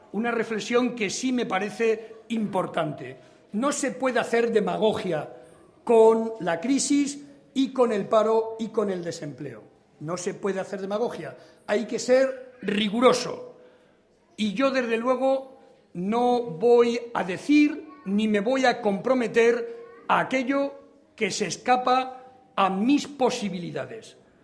La caravana del PSOE en Fuensalida, La Puebla de Montalbán y Cebolla